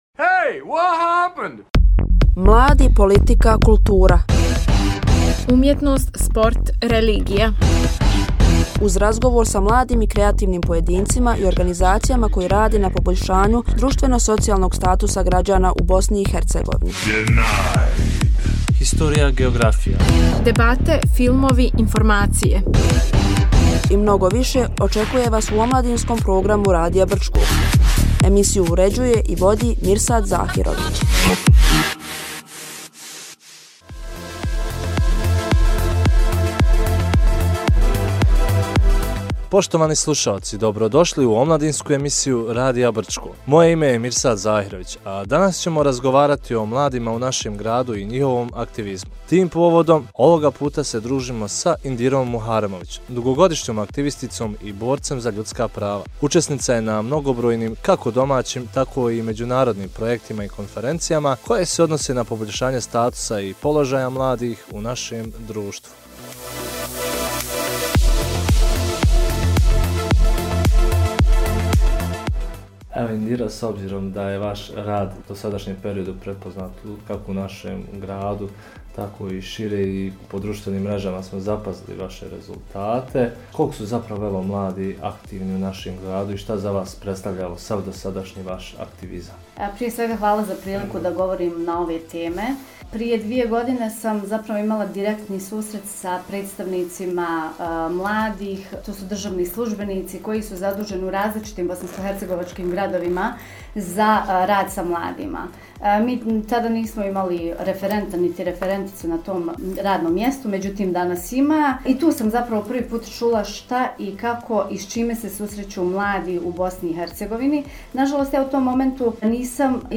Gost Omladinske emisije